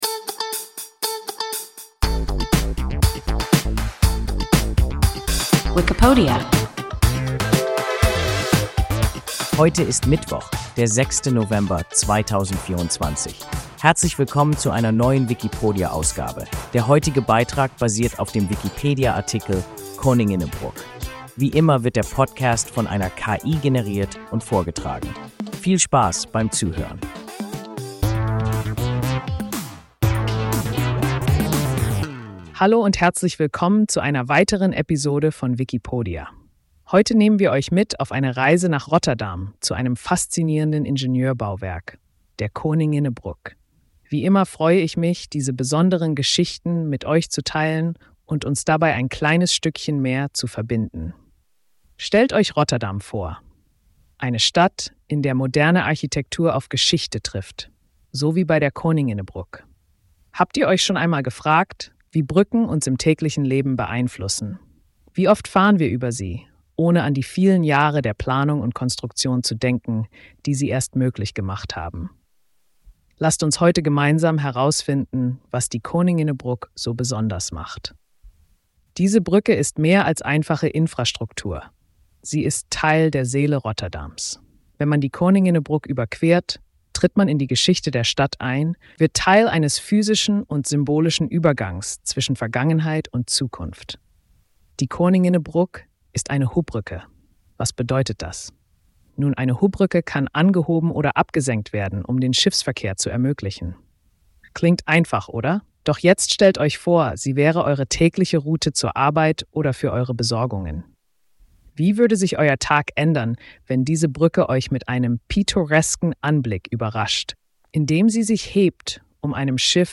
Koninginnebrug – WIKIPODIA – ein KI Podcast